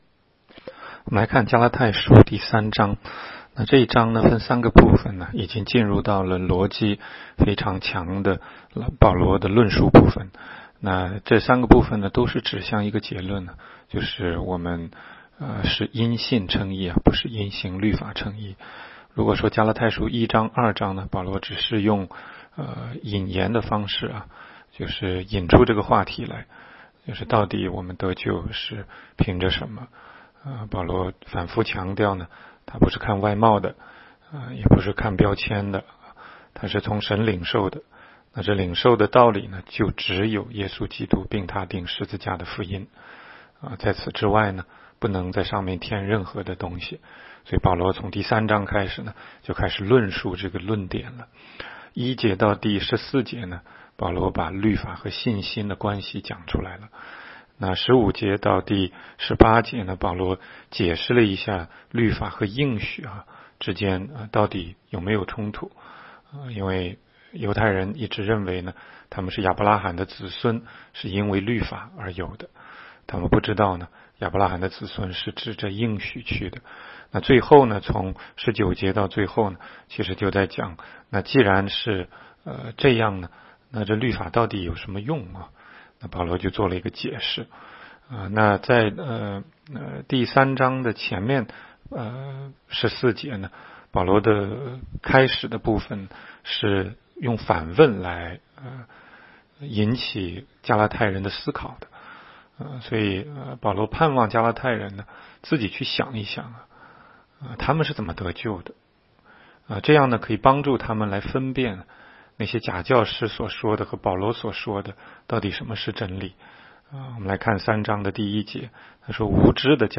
16街讲道录音 - 每日读经